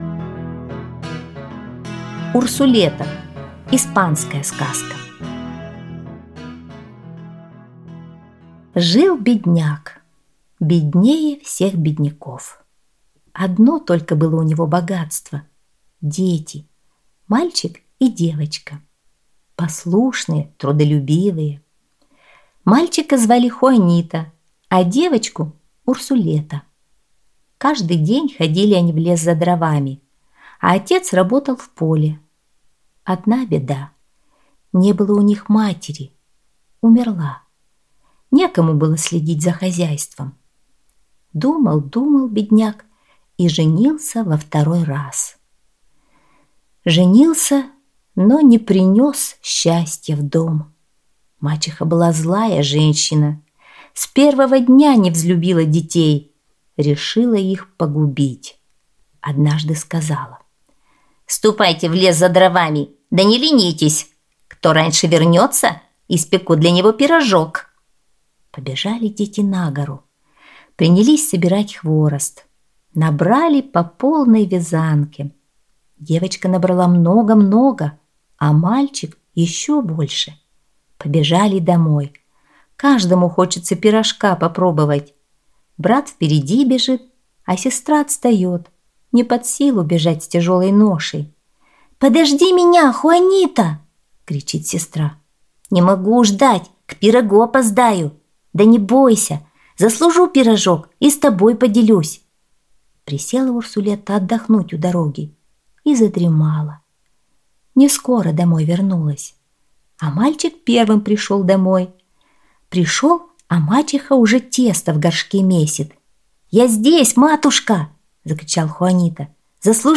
Урсулета - испанская аудиосказка - слушать онлайн